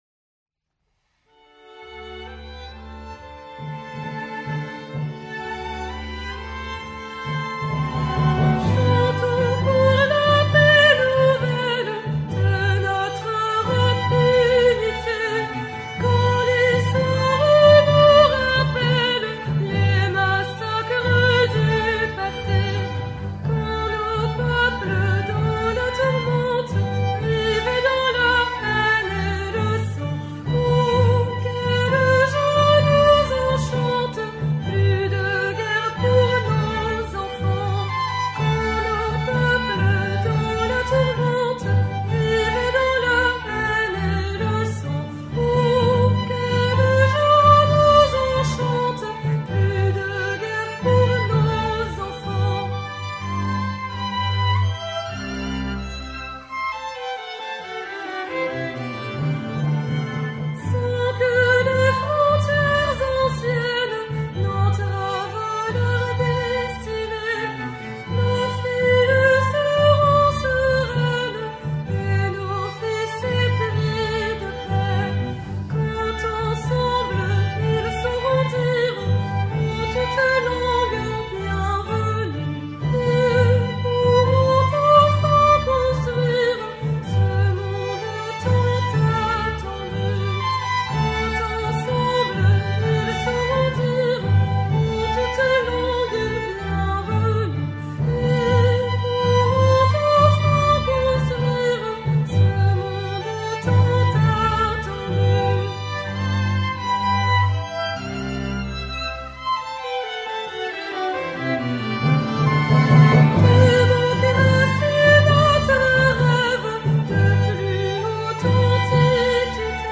SONNERIES MILITAIRES ADAPTÉES AUX CÉRÉMONIES
Marches militaires [ 11 novembre ]